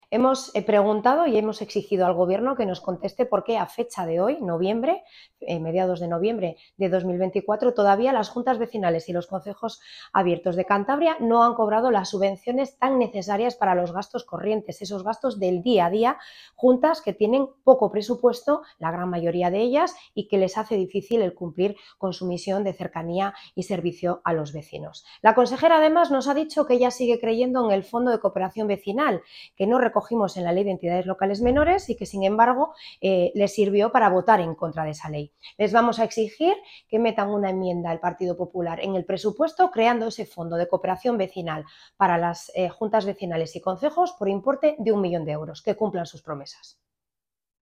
Ver declaraciones de Rosa Díaz, diputada del PArtido regionalista de Cantabria y portavoz del PRC en materia de Administración Pública.